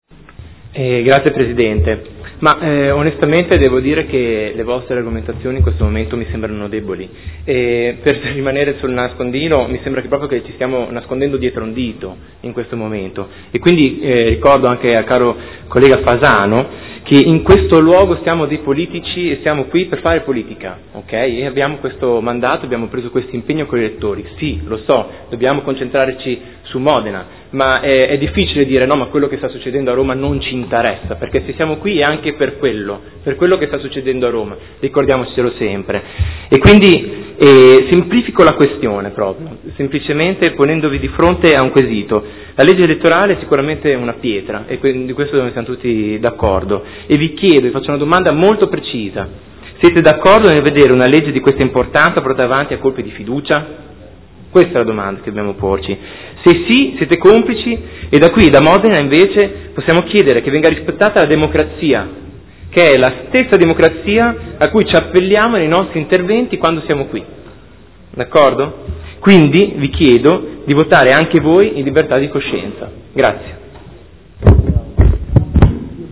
Seduta del 30/04/2015 Odg 58621 su Italicum